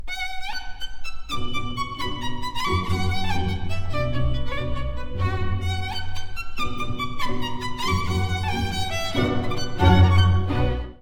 Tonalidad: Si menor.
Duración: 8 compases 6/8.
Cambios: 2.
Obsérvese que el V de la semicadencia (final de semifrase en c.4) no tiene, como es habitual, la séptima, mientras que sí la tienen los V que resuelven en I dentro de una frase.